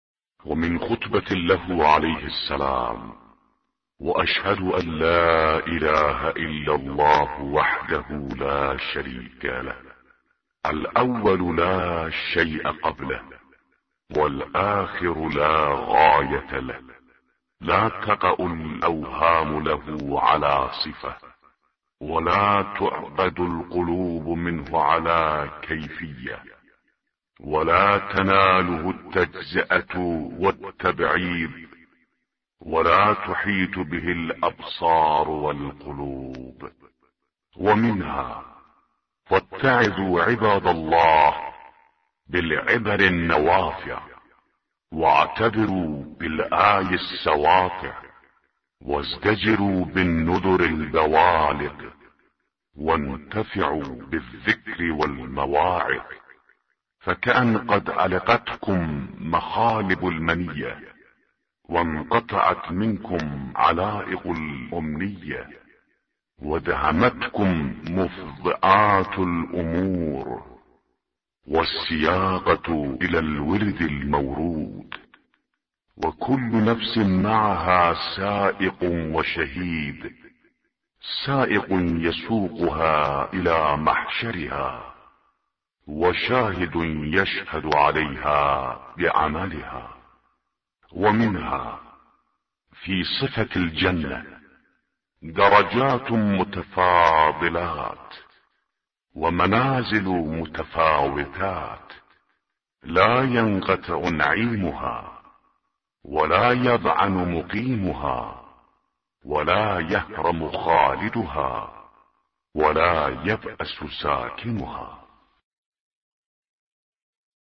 به گزارش وب گردی خبرگزاری صداوسیما؛ در این مطلب وب گردی قصد داریم، خطبه شماره ۸۵ از کتاب ارزشمند نهج البلاغه با ترجمه محمد دشتی را مرور نماییم، ضمنا صوت خوانش خطبه و ترجمه آن ضمیمه شده است: